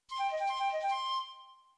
Mystic Flute Flutter 3
bonus-sound film-production flute flutter game-development intro magic magical-flute-sound sound effect free sound royalty free Movies & TV